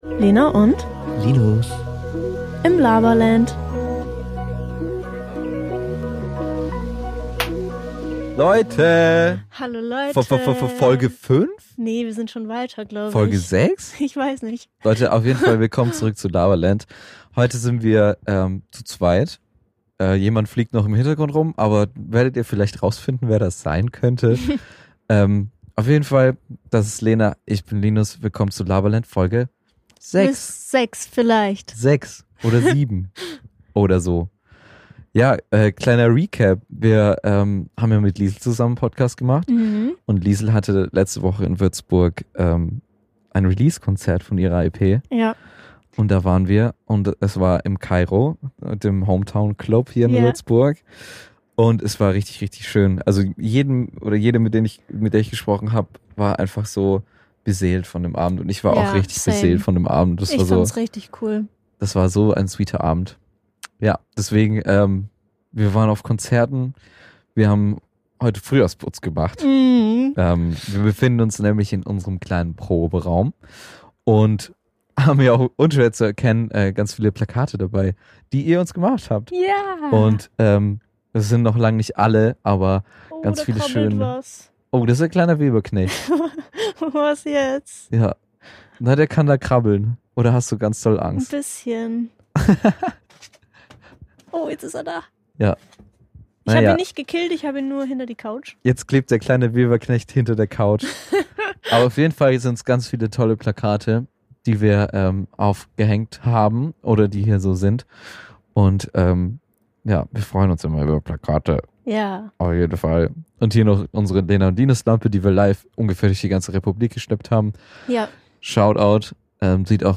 Wir sitzen zu zweit im frisch aufgeräumten Proberaum und es gibt jede Menge zu besprechen!